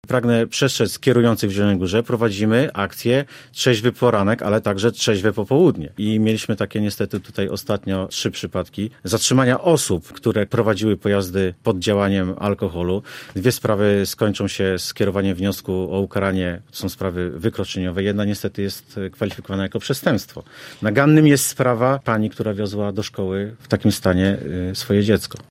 Mł. insp. Maciej Sipek, Komendant Miejski Policji w Zielonej Górze, który był dzisiaj gościem „Rozmowy po 9”, przypominał o prowadzonych kontrolach trzeźwości na terenie miasta i powiatu: